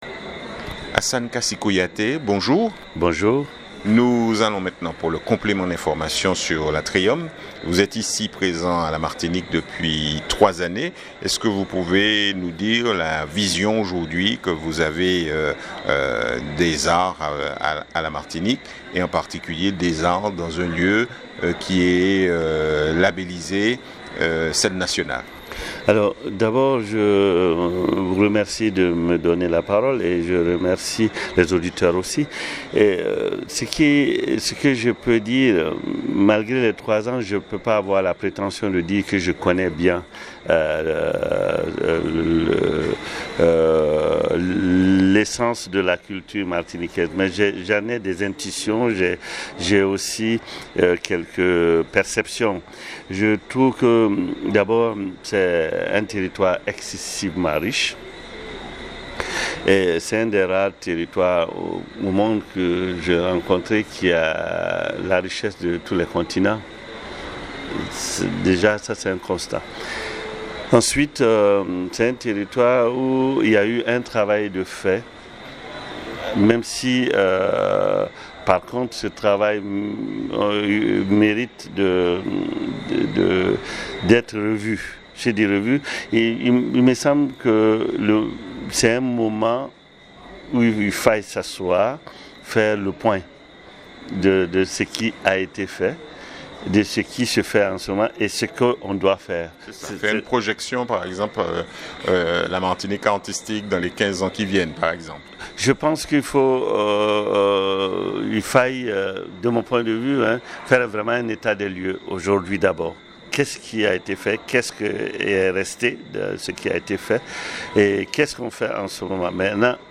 Invité du jour